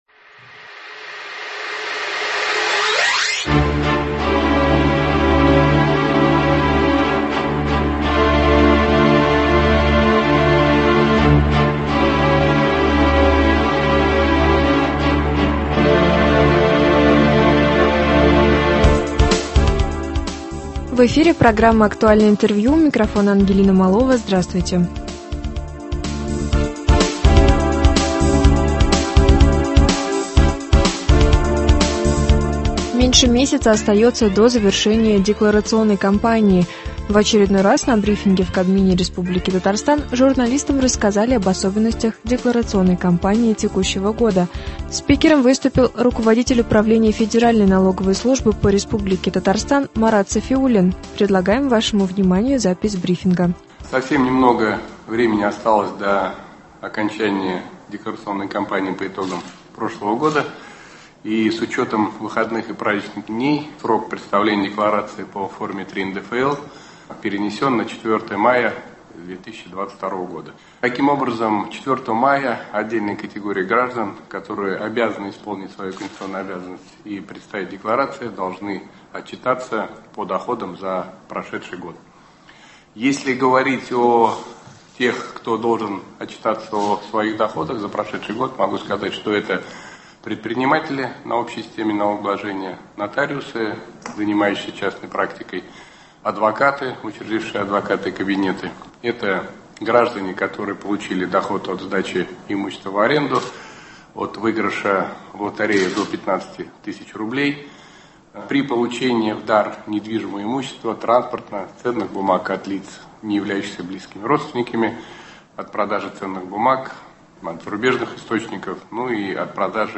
Актуальное интервью (13.04.22)
В очередной раз на брифинге в Кабмине РТ журналистам рассказали об особенностях декларационной кампании текущего года.Спикером выступил Руководитель Управления Федеральной налоговой службы по Республике Татарстан Марат Сафиуллин.